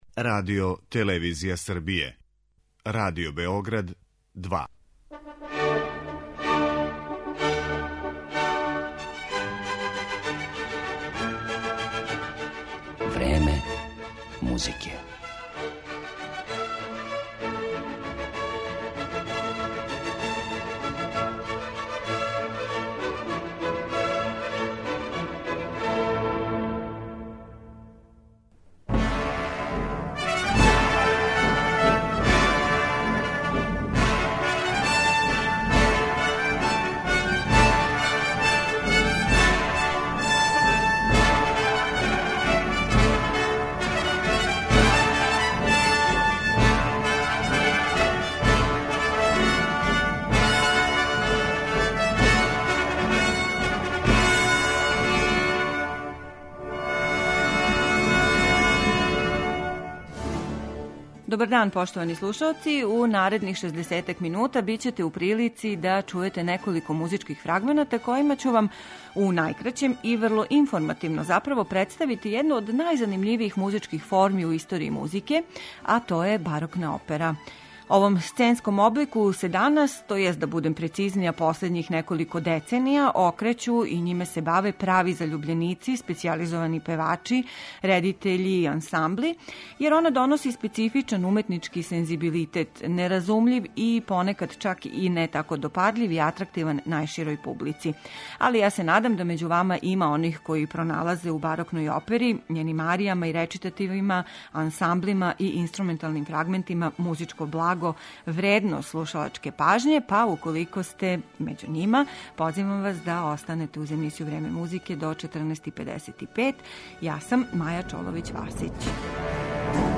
Имаћете прилику да чујете фрагменте из неких од најпознатијих барокних опера, чији су аутори, између осталих, Монтеверди, Персл, Лили, Кавали и Хендл.